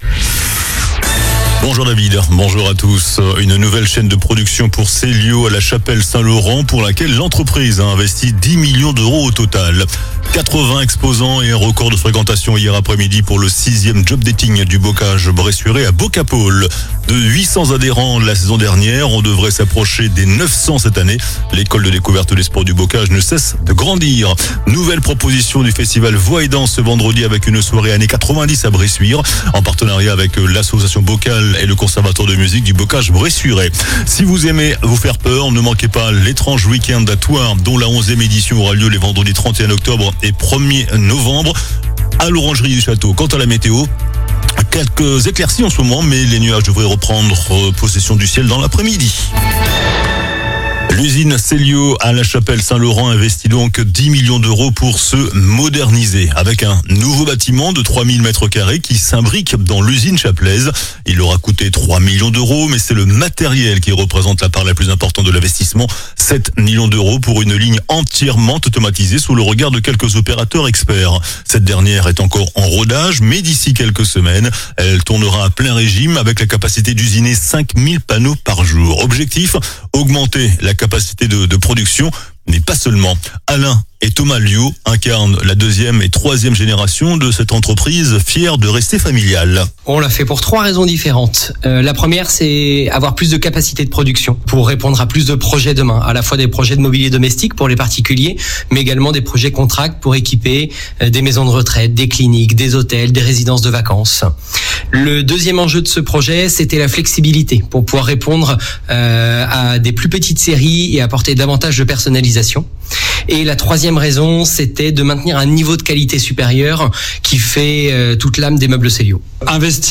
JOURNAL DU VENDREDI 24 OCTOBRE ( MIDI )